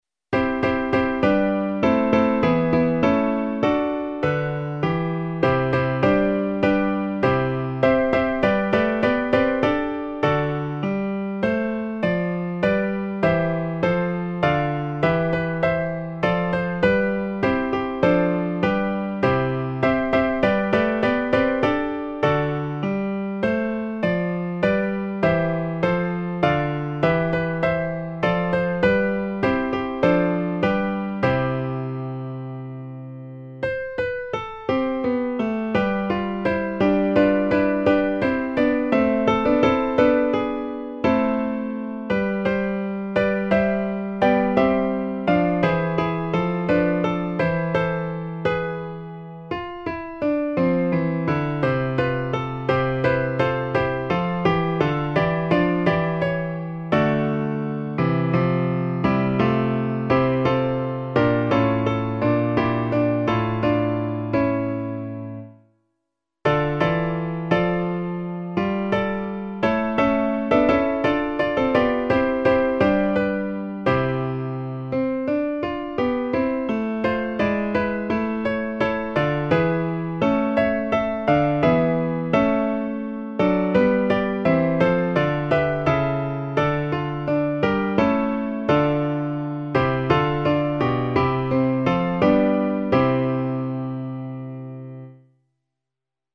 A 4 voces (Tiple I, II, Tenor y Bajo)